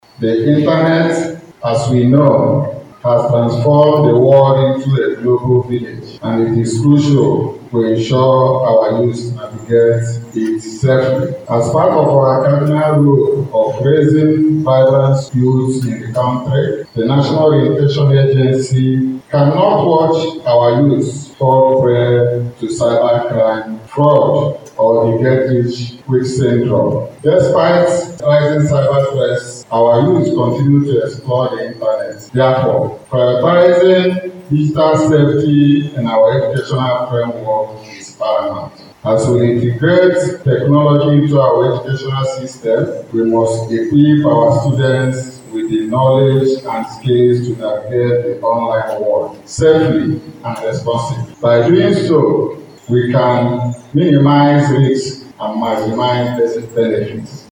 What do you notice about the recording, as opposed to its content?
This was made known during a Digital Safety Conference for Secondary Schools organised by SieDi Hub, a non-governmental organisation, in collaboration with National Orientation Agency (NOA) and other development partners in Umuahia.